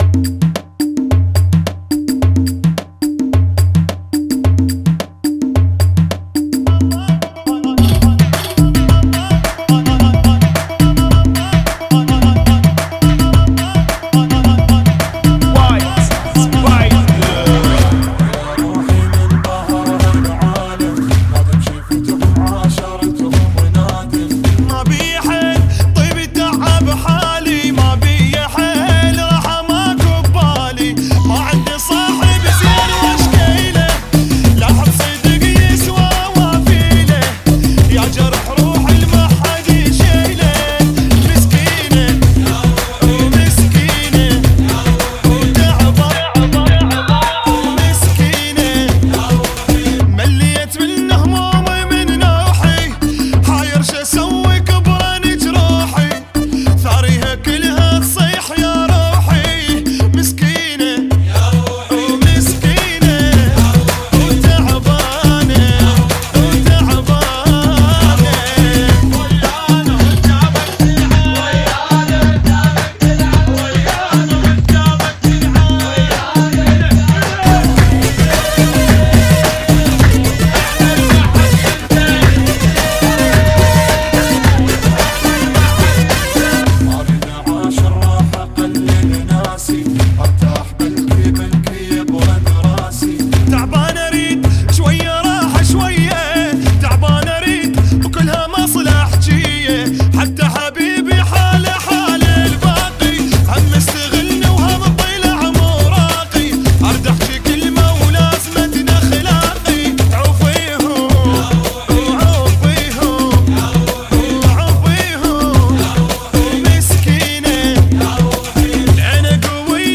Funky [ 108 Bpm ]